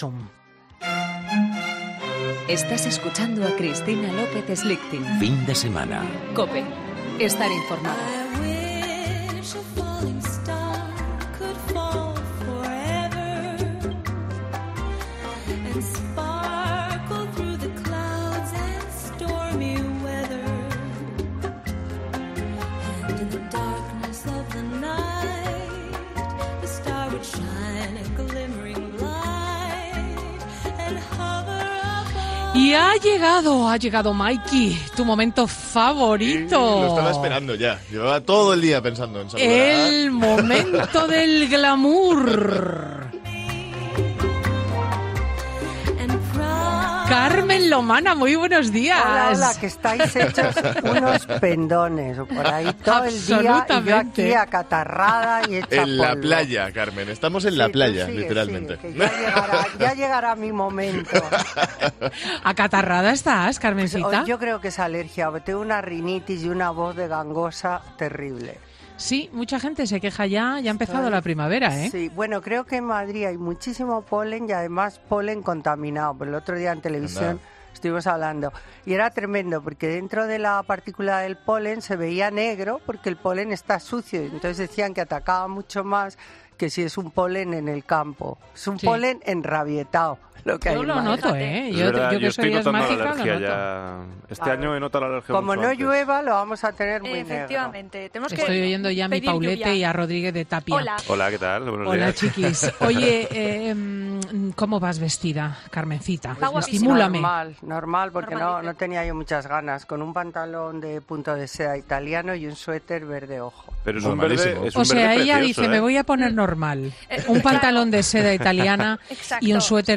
AUDIO: Carmen Lomana responde todas nuestras dudas sobre protoclo en el consultorio Saber Estar